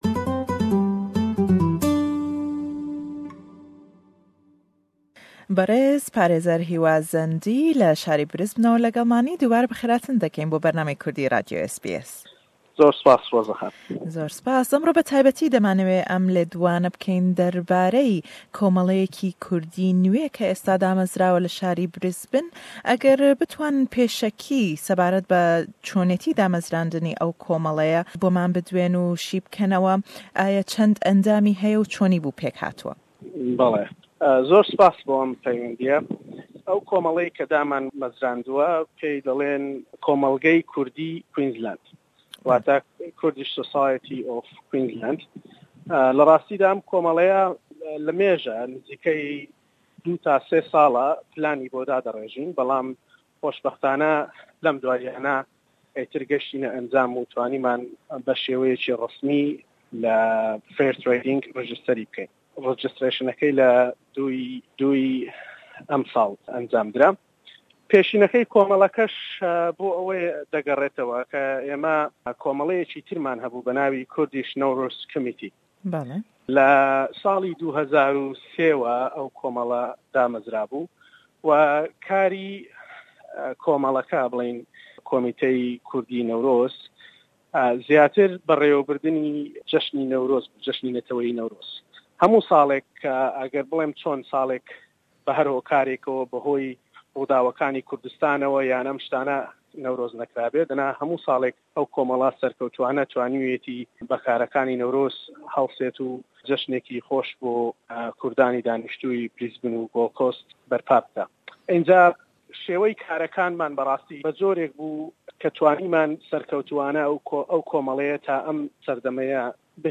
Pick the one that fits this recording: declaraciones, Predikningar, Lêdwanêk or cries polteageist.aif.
Lêdwanêk